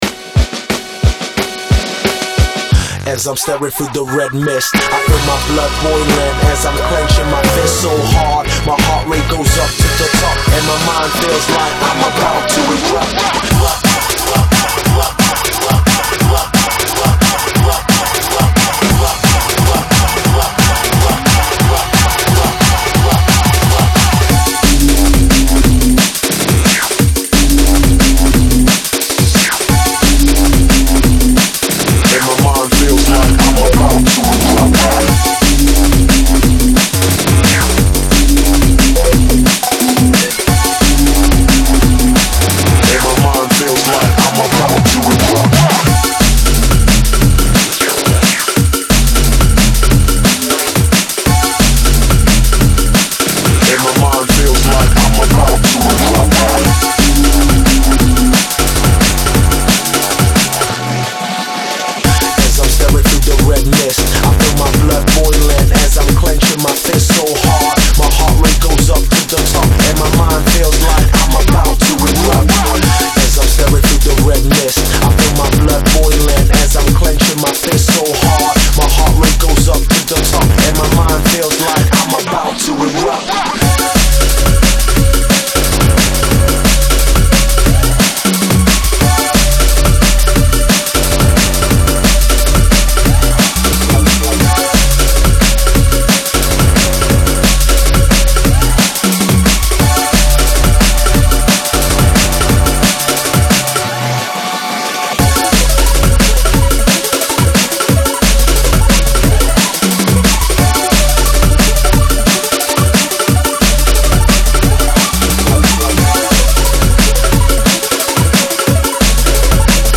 drum-and-bass